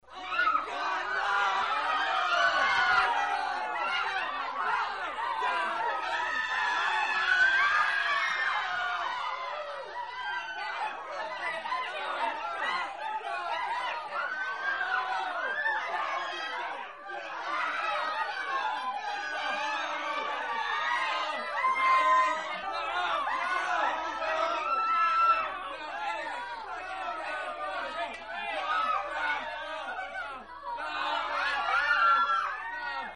Звуки паники
В коллекции представлены тревожные крики, хаотичные шаги, гул взволнованной толпы и другие эффекты для создания напряженной атмосферы.